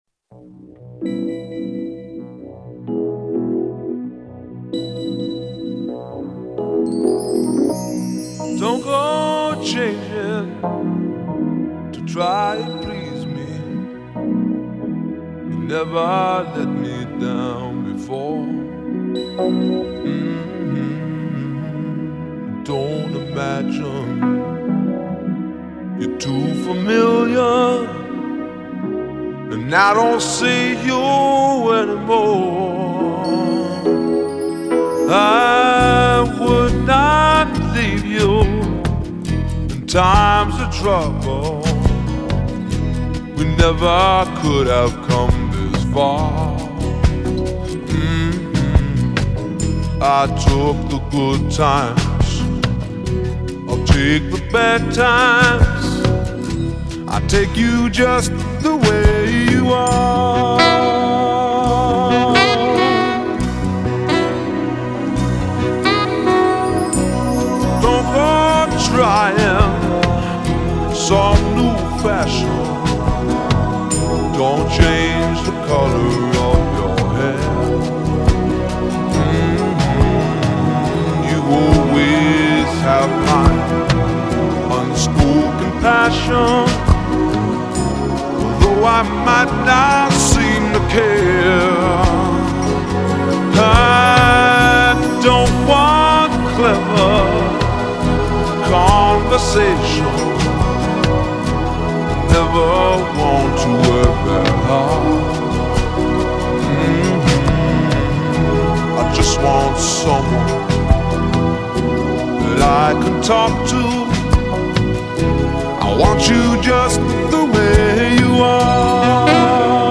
Настолько плохо пел, что даже ругать постеснялись.